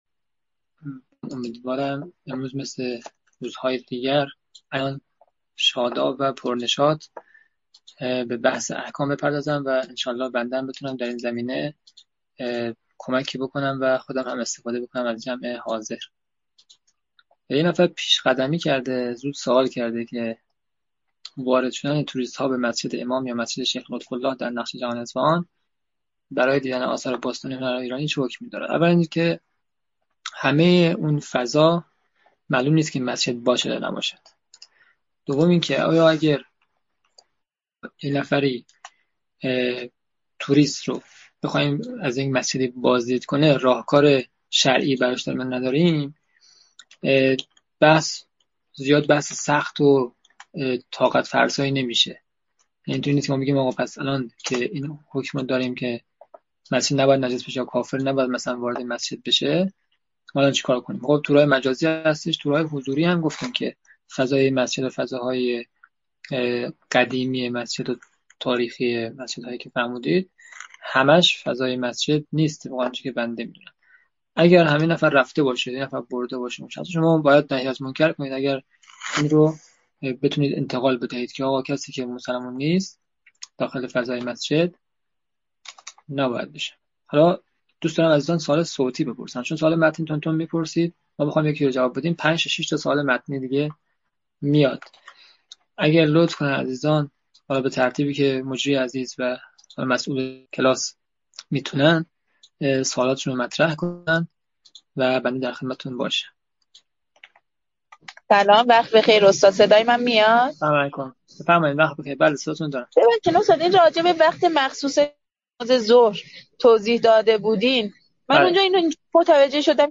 آموزش احکام (احکام عبادات) - جلسه-پرسش-و-پاسخ-شذره-دوم